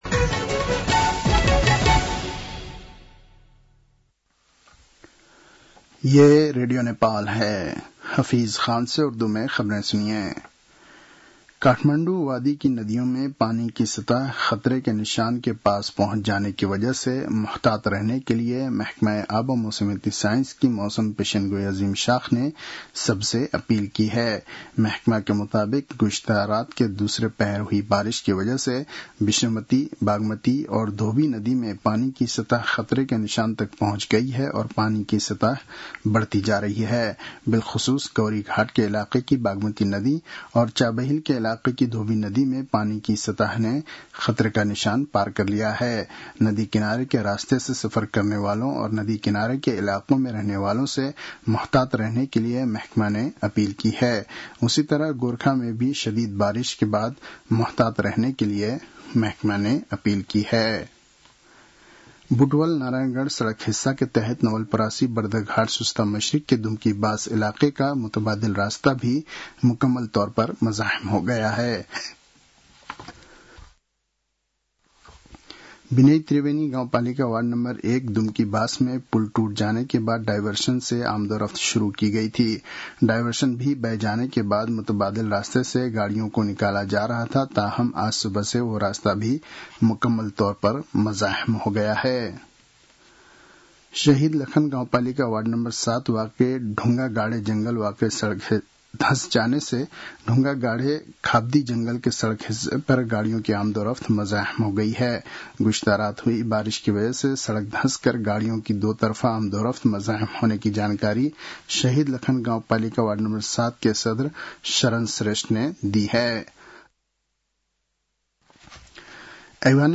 उर्दु भाषामा समाचार : ९ असार , २०८२